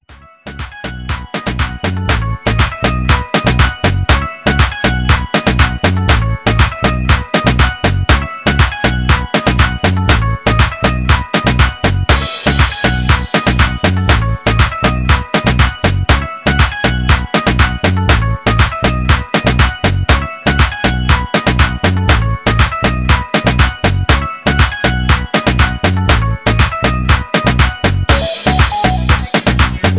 ONE OF MY BEST SOCA INSTRUMENTAL by
Tags: soca